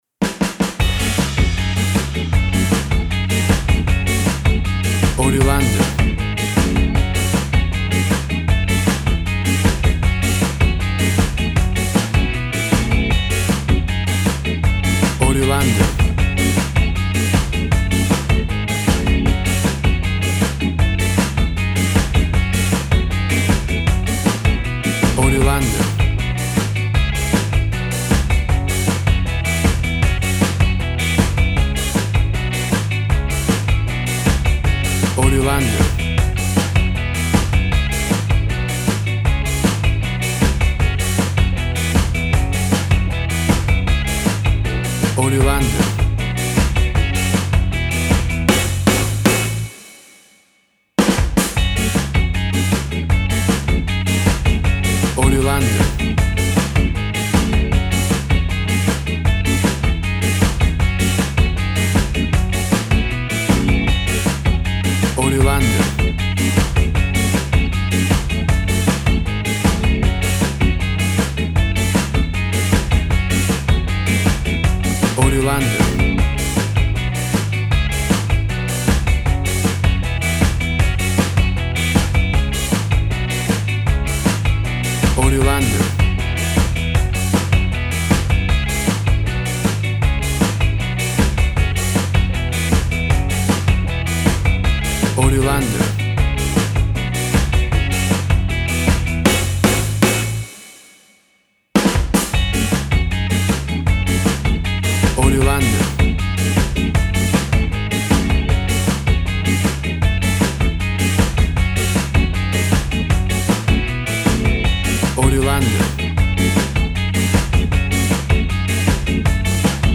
Classic reggae music with that skank bounce reggae feeling.
Tempo (BPM) 156